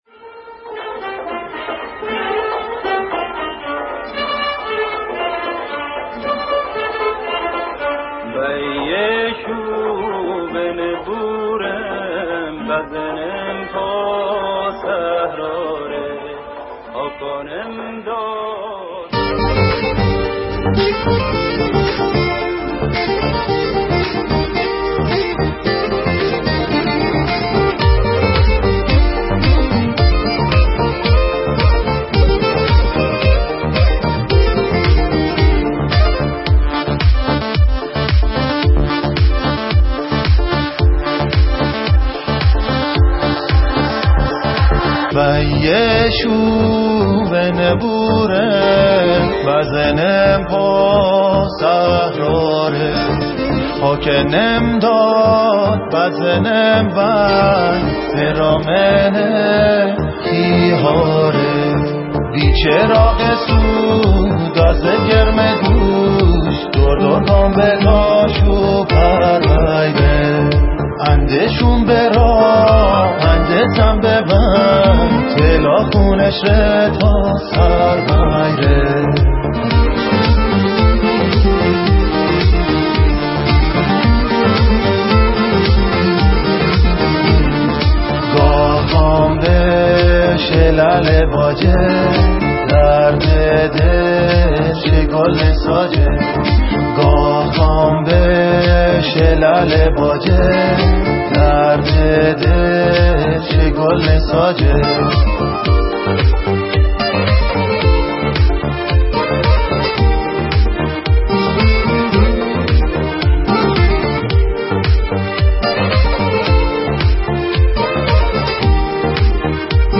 آهنگ جدید مازندرانی
آهنگ شاد